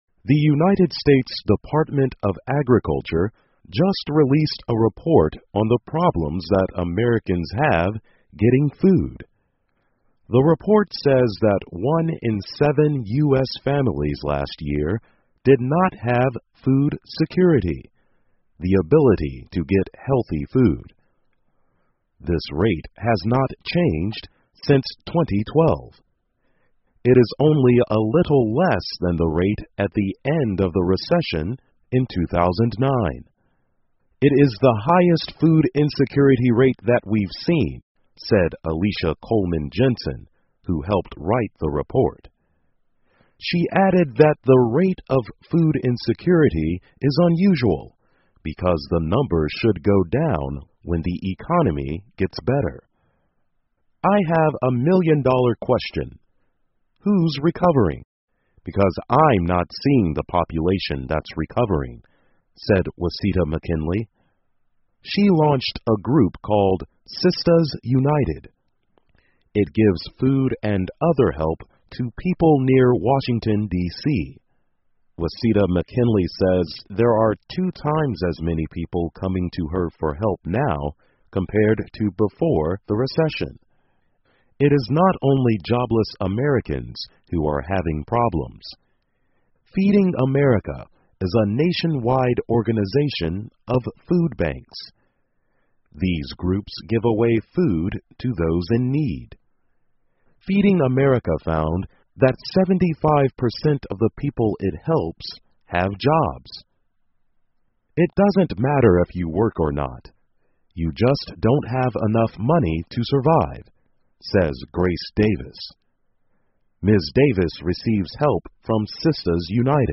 VOA慢速英语2014--七分之一的美国人受到饥饿威胁 听力文件下载—在线英语听力室